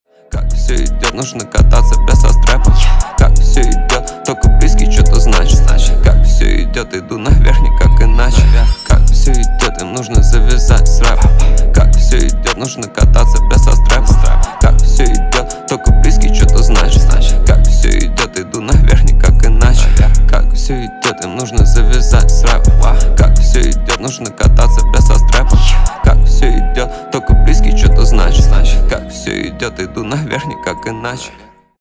Рэп и Хип Хоп # спокойные